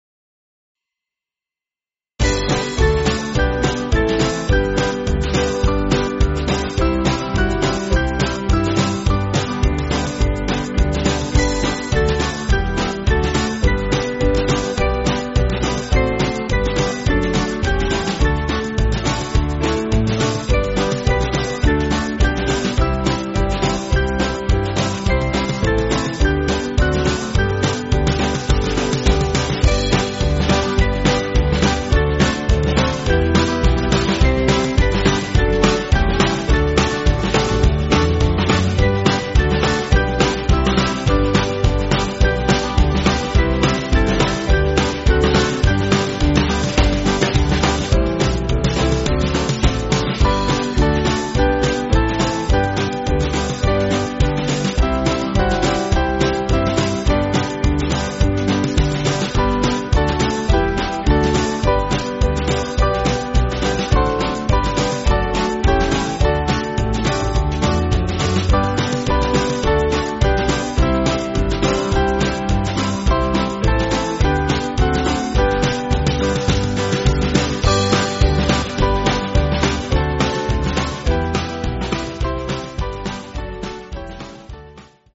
Small Band
(CM)   6/G